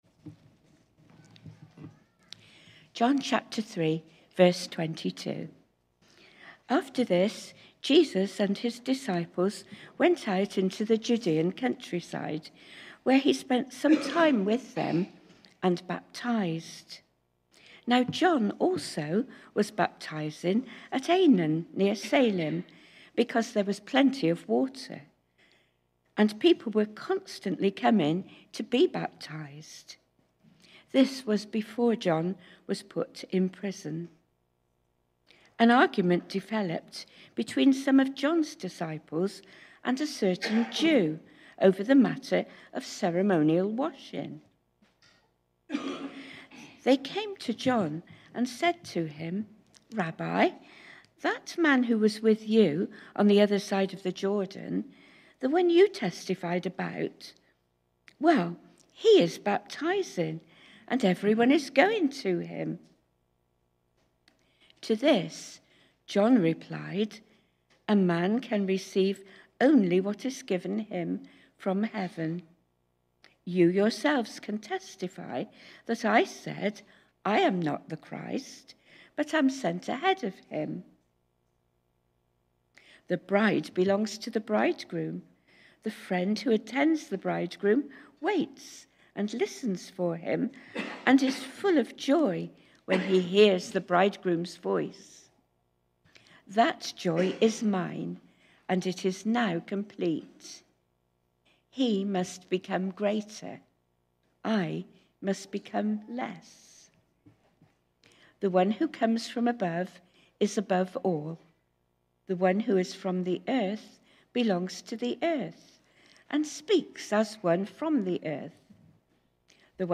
Emmanuel Church Chippenham | Sermons